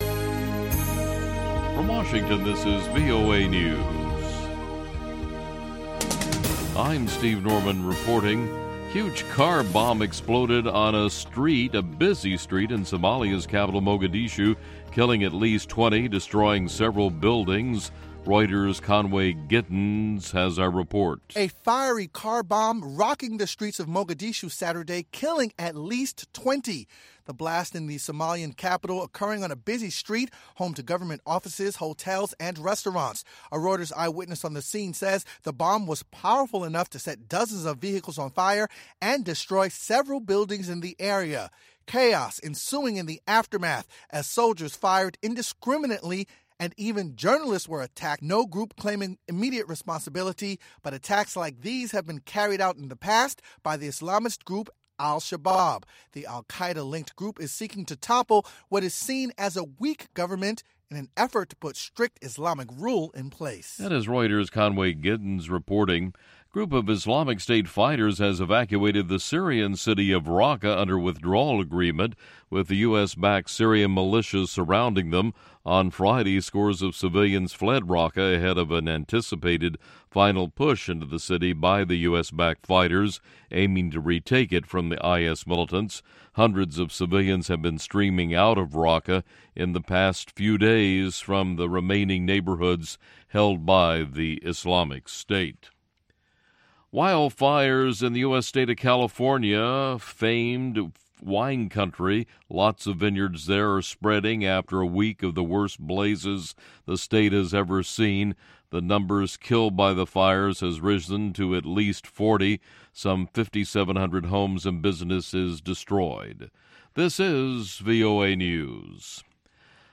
Of course we'll also hear another fabulous pan-African mix of music that comes from Zimbabwe, Nigeria, Mali, Kenya, Senegal, and Ghana.